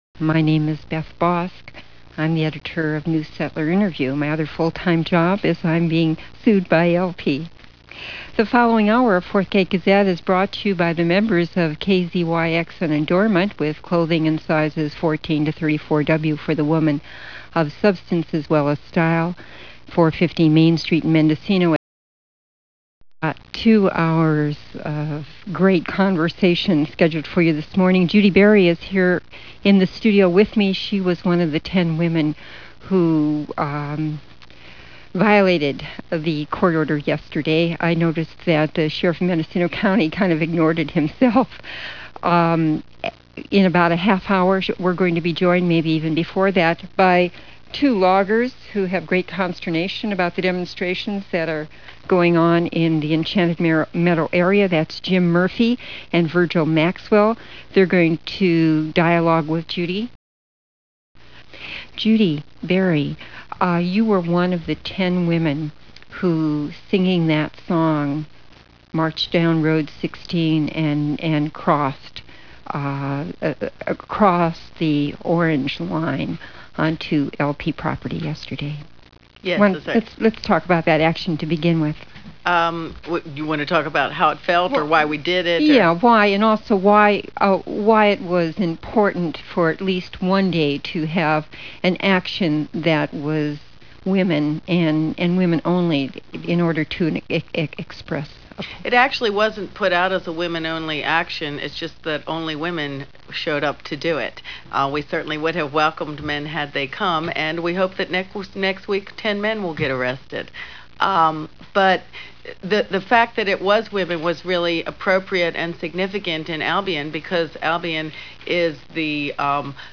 KZYX FM in Boonville, California
talk show